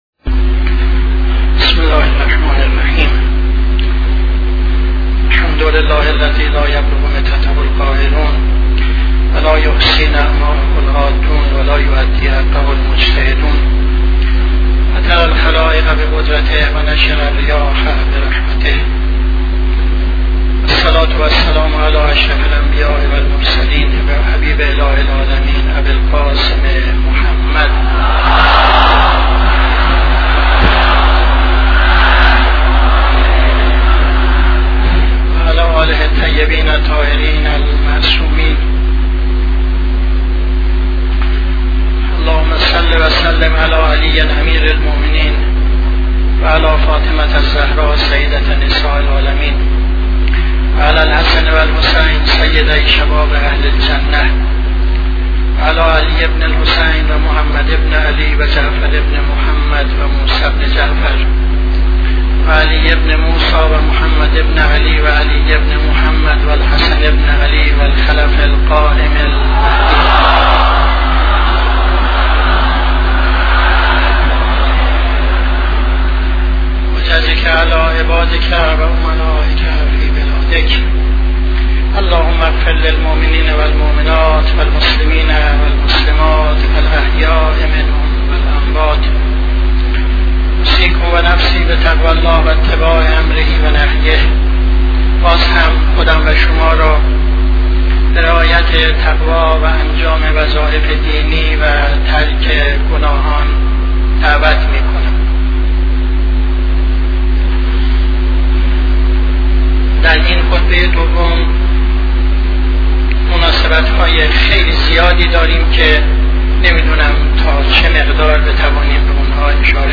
خطبه دوم نماز جمعه 28-06-76